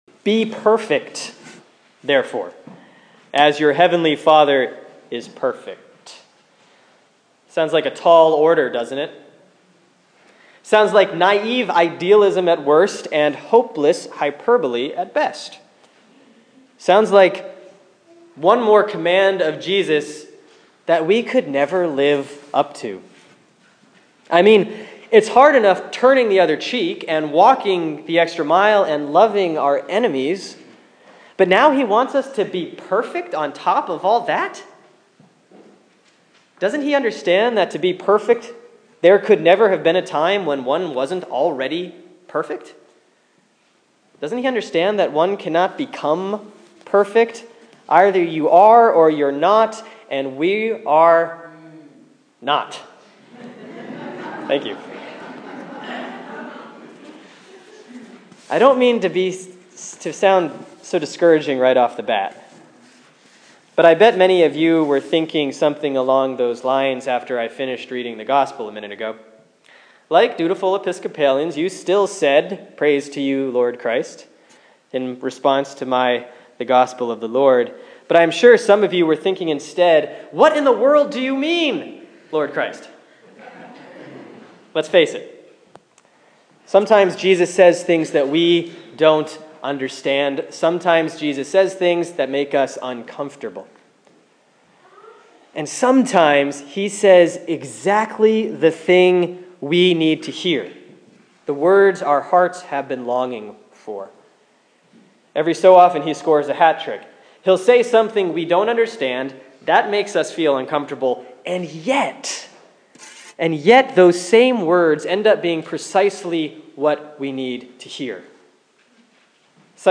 Sermon for Sunday, February 23, 2014 || Epiphany 7A || Matthew 5:38-48